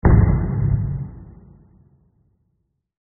explosion0.mp3